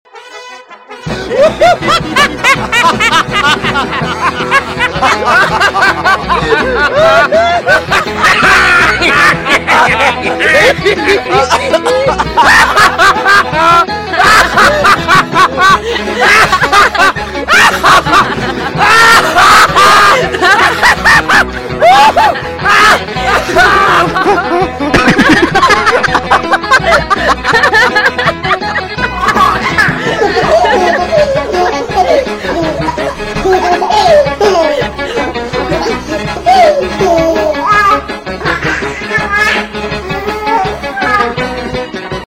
Categoría Graciosos